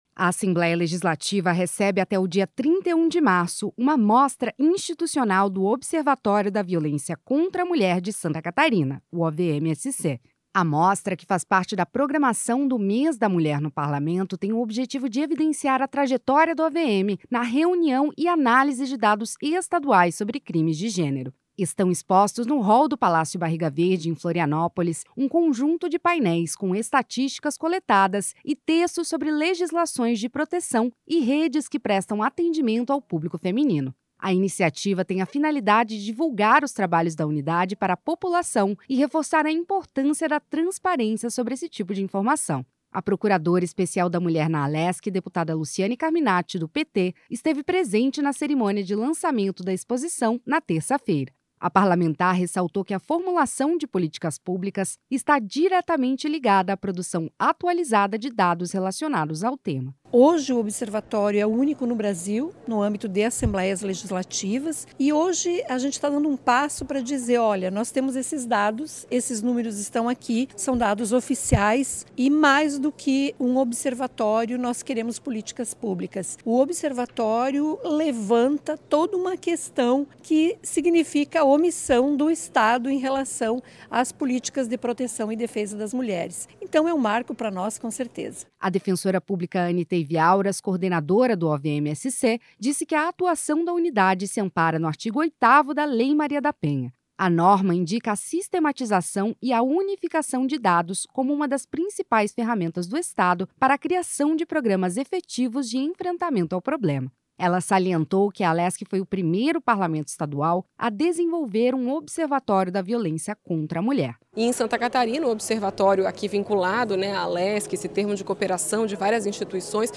Entrevistas com:
- deputada Luciane Carminatti (PT), procuradora Especial da Mulher na Alesc;